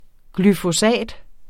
Udtale [ glyfoˈsæˀd ]